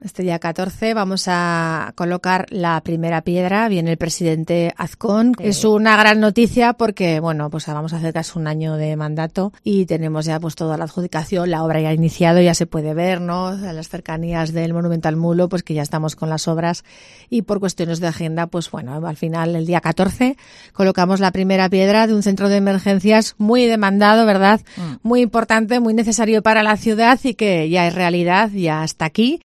Lorena Orduna es la alcaldesa de Huesca